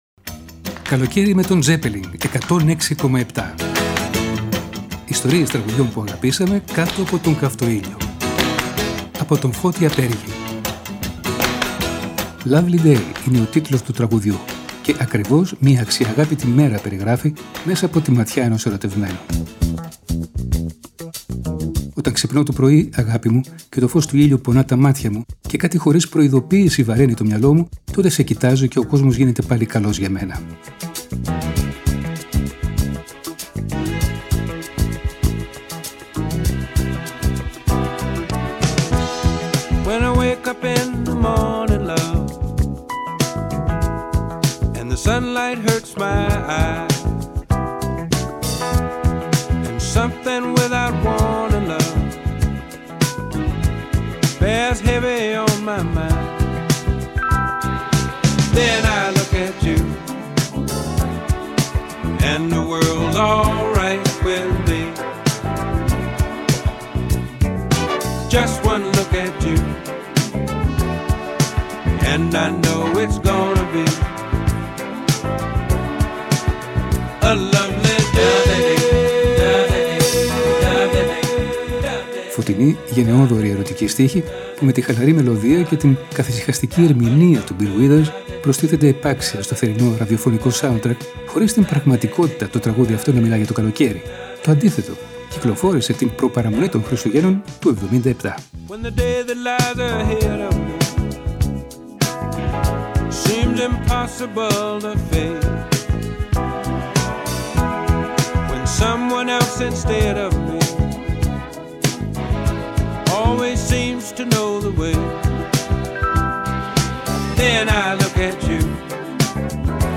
Φωτεινοί, γενναιόδωροι ερωτικοί στίχοι, που με τη χαλαρή μελωδία και την καθησυχαστική ερμηνεία του Μπιλ Γουίδερς προστίθενται επάξια στο θερινό ραδιοφωνικό σάουντρακ,, χωρίς στην πραγματικότητα το τραγούδι να μιλά για το καλοκαίρι.